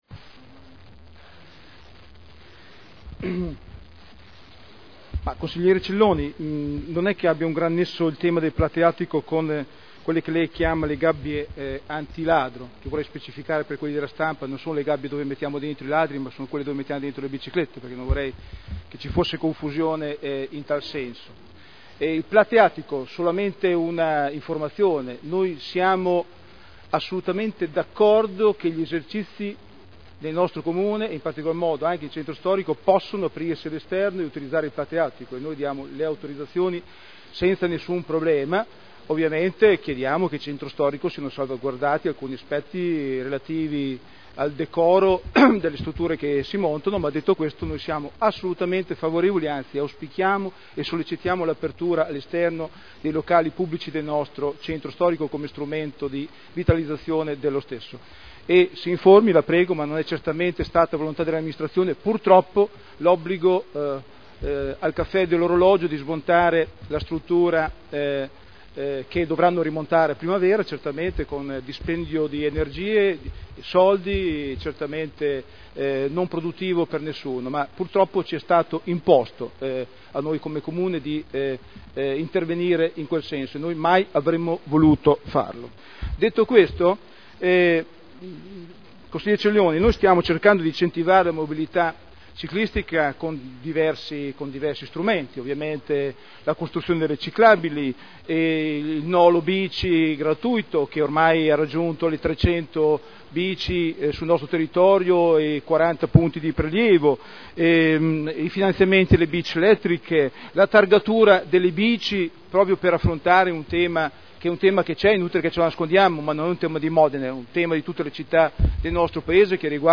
Daniele Sitta — Sito Audio Consiglio Comunale
Seduta del 14/12/2009. Disapprovazione delle gabbie anti-ladro per le biciclette, in quanto simbolo di una società poco garante della sicurezza cittadina, oltre che palliativo alla repressione del prospero traffico cittadino di biciclette rubate